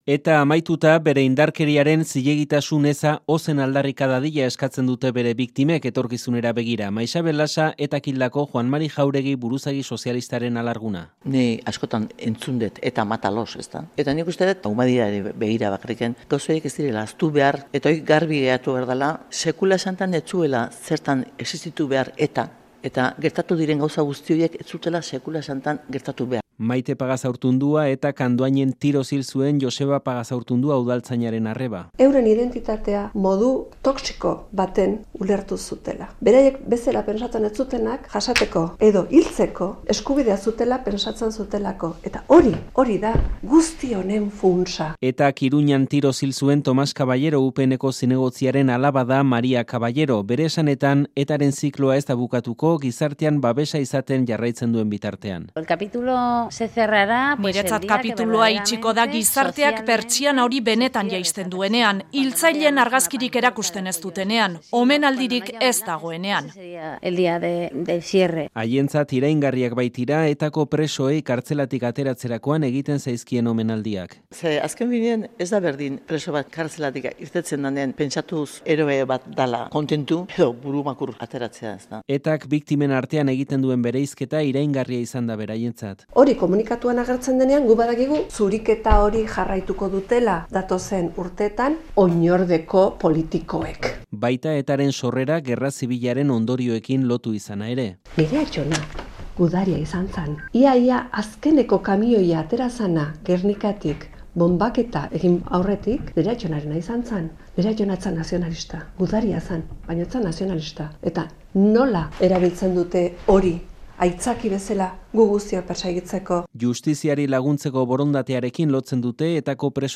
Audioa: ETAren amaieraz, 3 biktimarekin solastu gara.